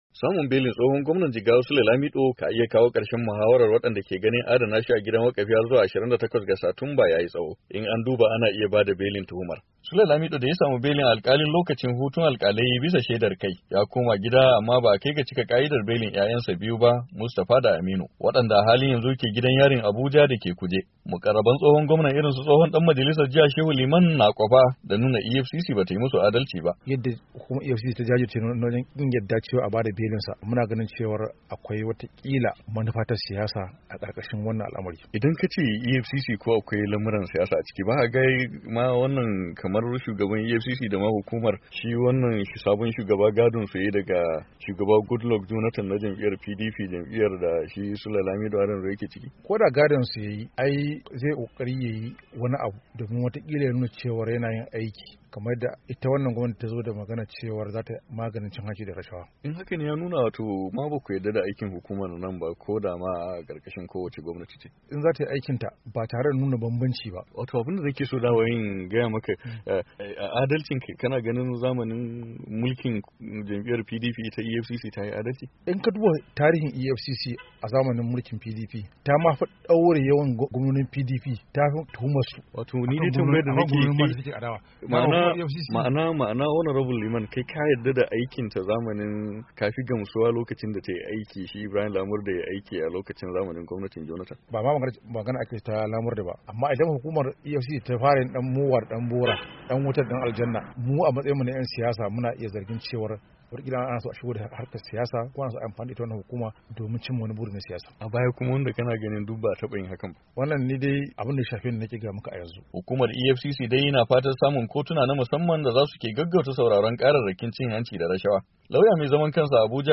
Rahoton belin Sule Lamido - 3'00"